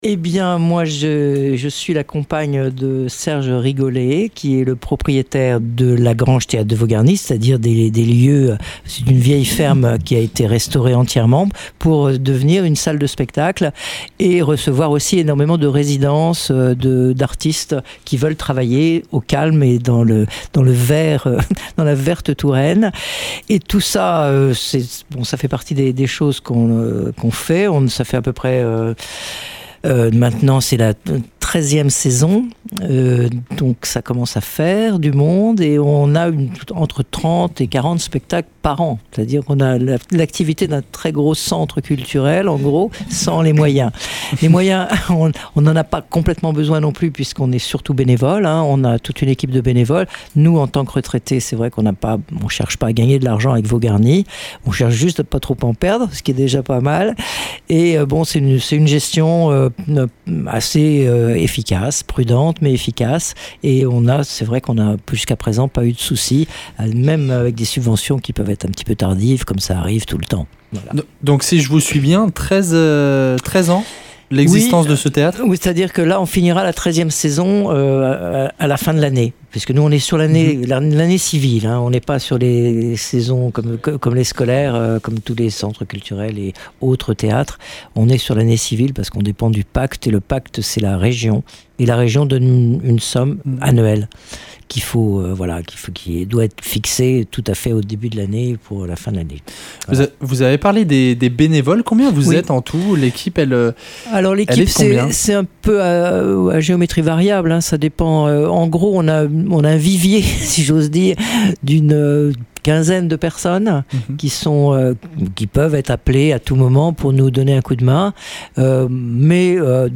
Interview !